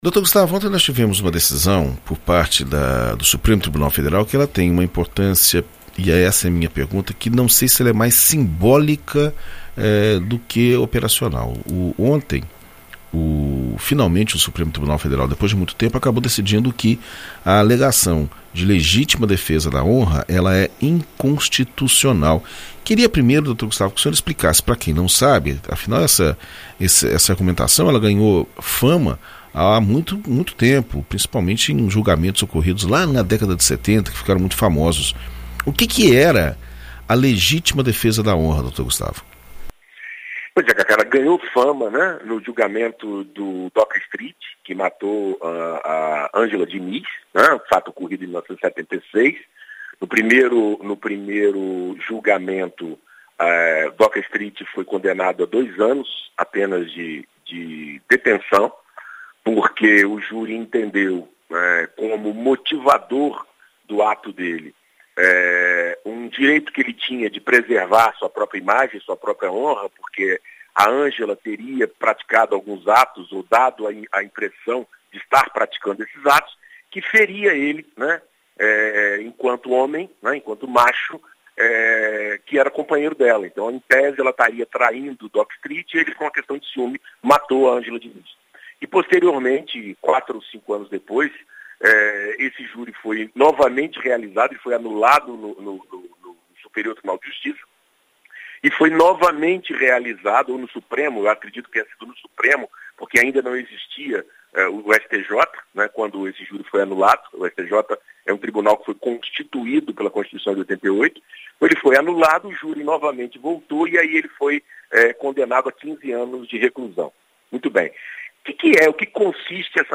Na coluna “Direito para Todos” desta quarta-feira (02) na BandNews FM Espírito Santo